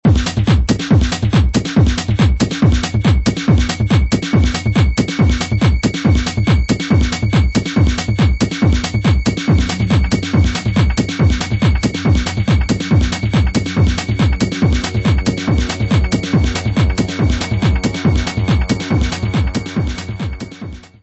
: stereo; 12 cm
Music Category/Genre:  Pop / Rock